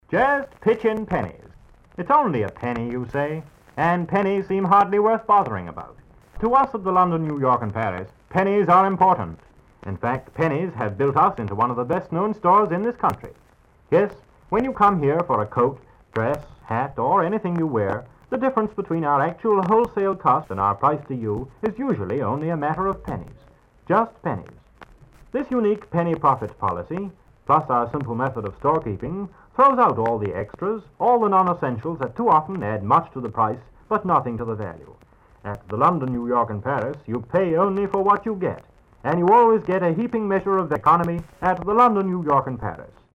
Stories: Archival radio commercials Broadcasting Corporation of Newfoundland circa 1945